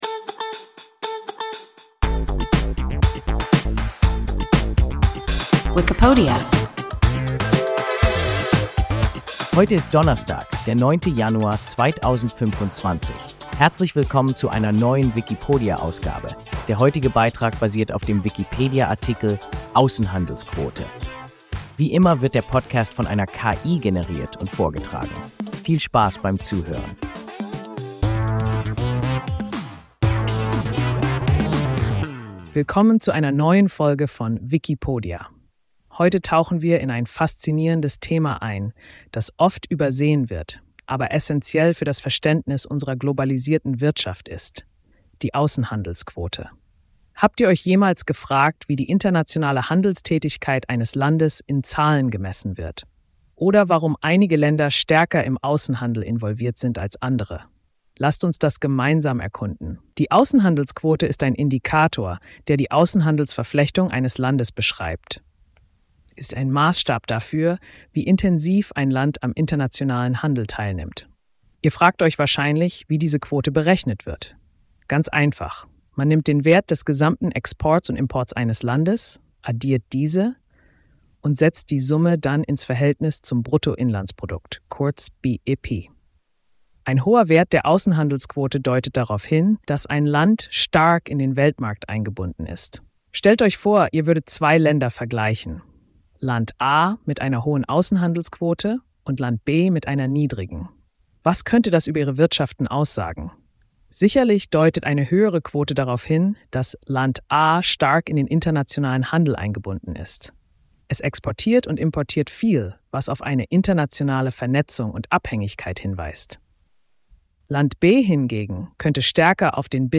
Außenhandelsquote – WIKIPODIA – ein KI Podcast